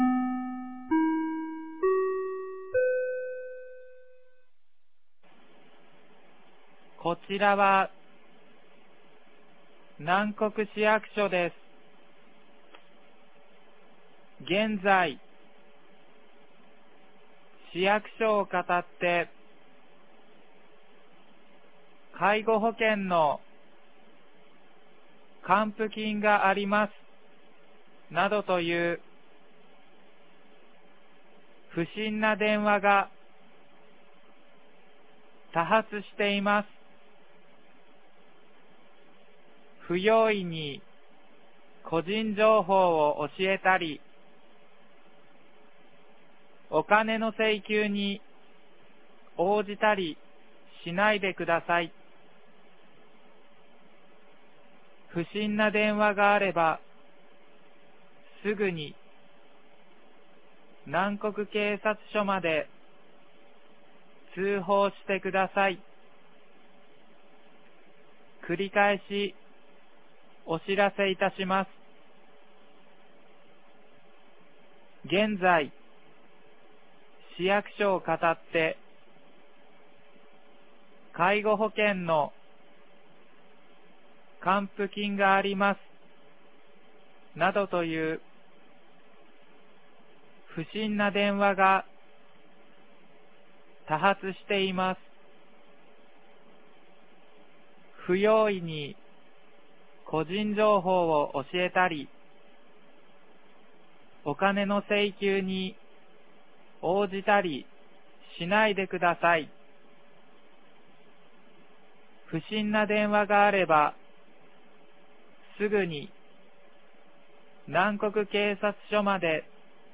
2023年11月15日 15時27分に、南国市より放送がありました。